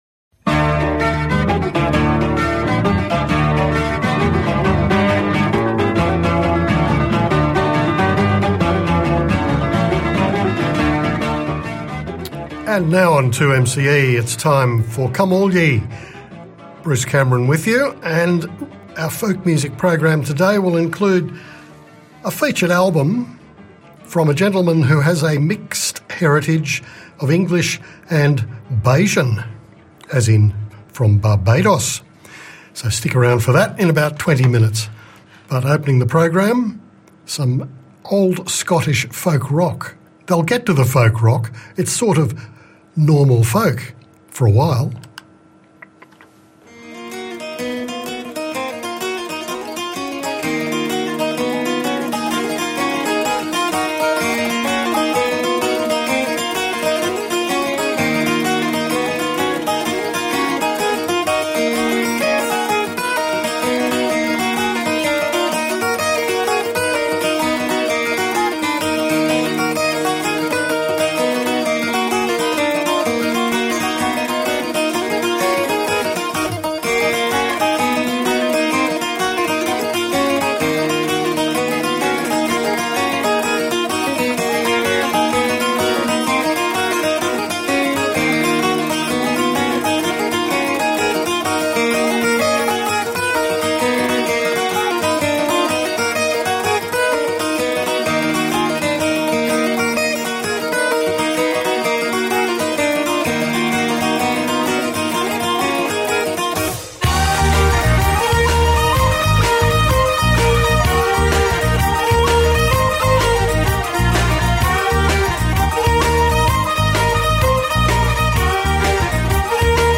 Mostly English folk songs with an occasional Bajan flavour.